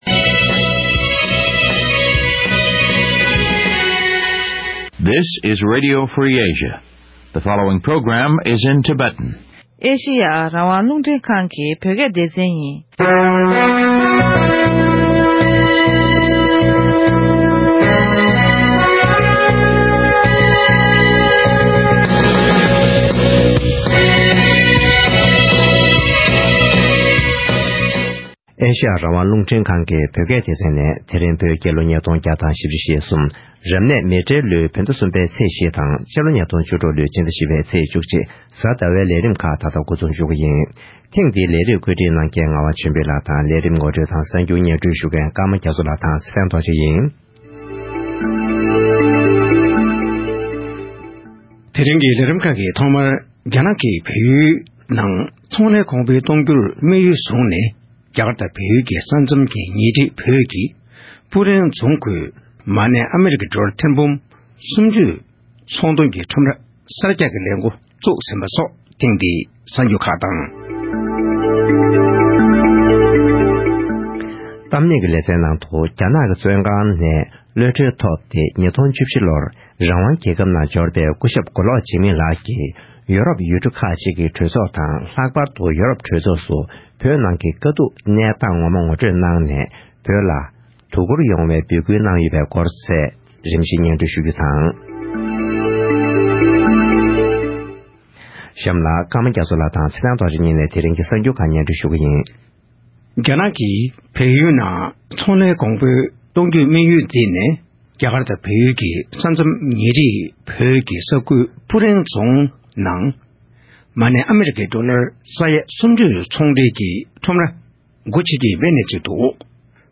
༄༅། །ཐེངས་འདིའི་གཏམ་གླེང་གྱི་ལེ་ཚན་ནང་།